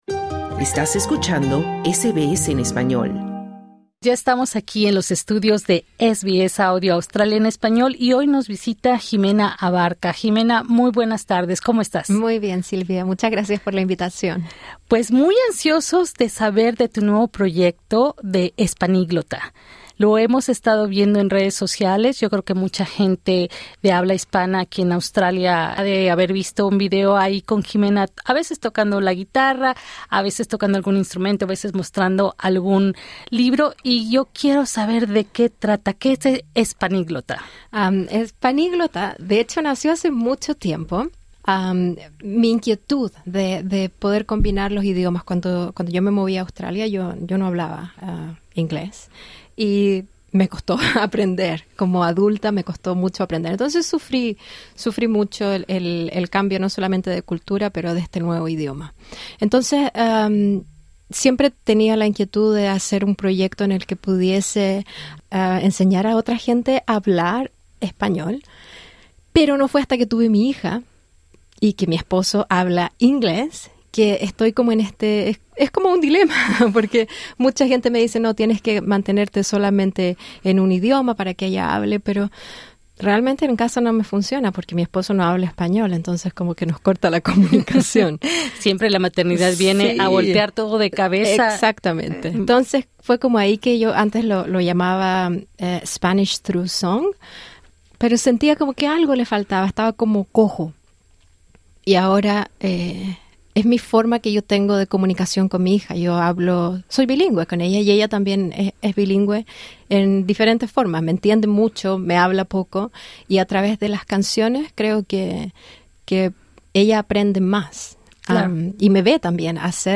en entrevista con SBS Audio